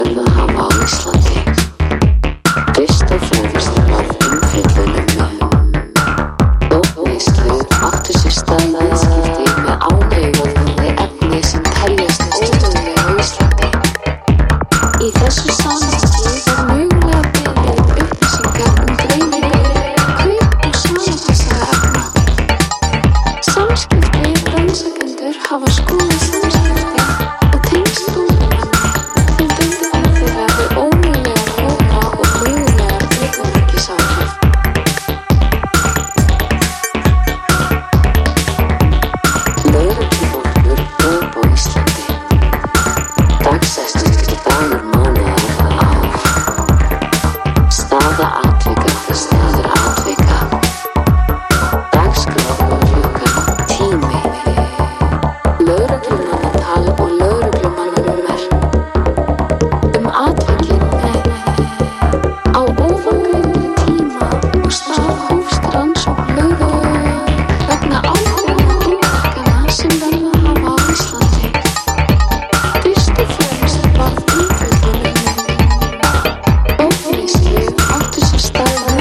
a complete pure analog ep